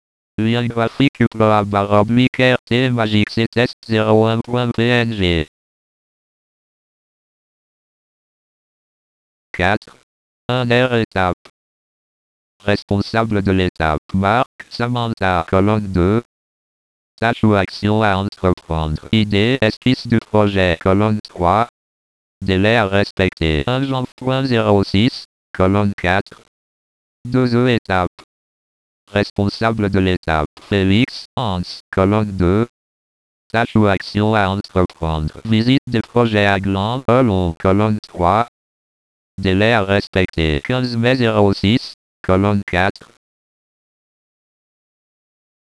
(wav, 0.96 Mo) Ecouter le logiciel de lecture d'écran lire ce tableau totalement inaccessible  (wav, 0.96 Mo)